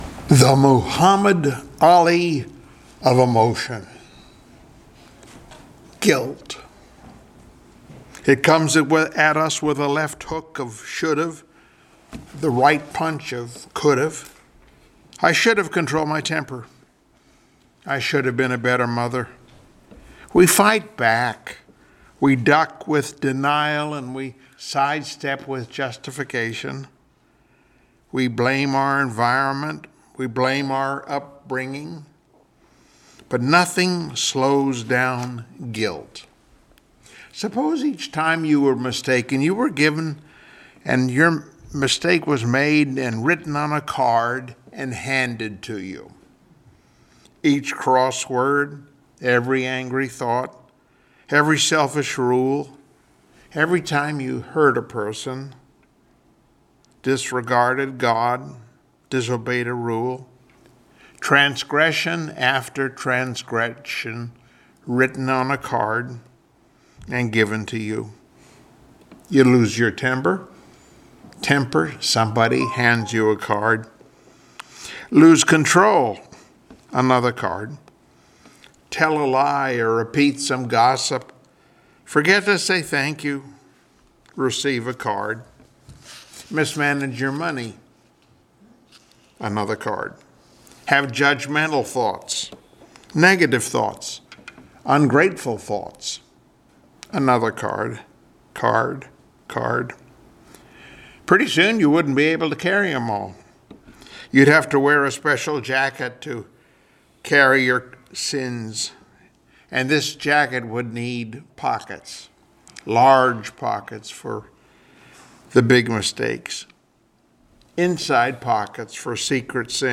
Service Type: Sunday Morning Worship Topics: Reality of Our Sin , Remedy for Our Sin , Removal of Our Sin